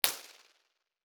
Room dimensions:                L*W*H = 9*7*4m3
Side walls:                               a = 0.10 ; 1% scattering
End walls:                                a = 0.50 ; 1% scattering
Ceiling:                                      a = 0.70 ; 1% scattering